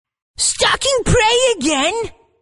Tags: lol Viegar voicelines League of legends